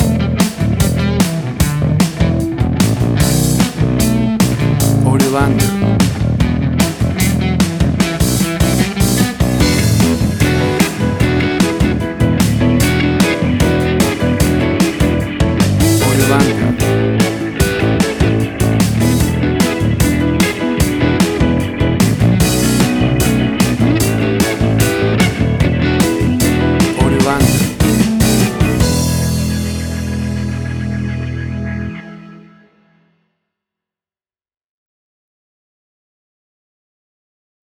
A cool swinging sixties Blues Rock music track.
Tempo (BPM): 150